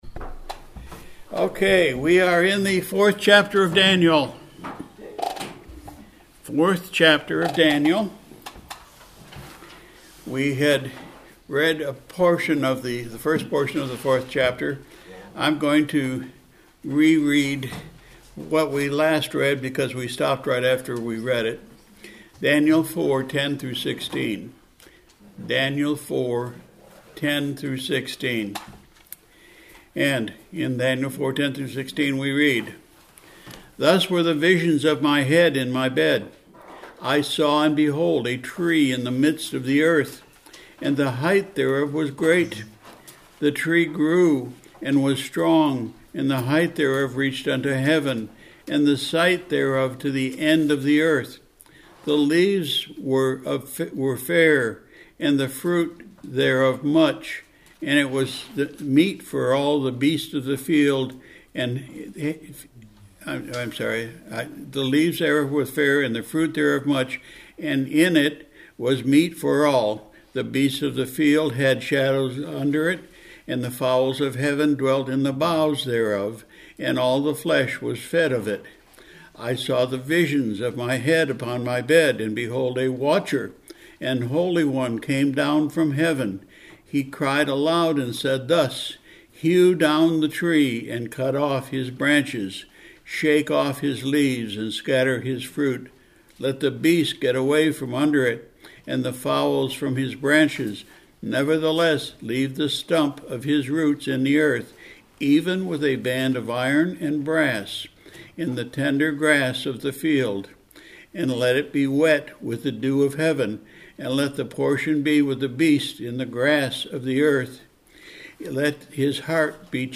March 9, 2025 – PM Service – Daniel Chapter 4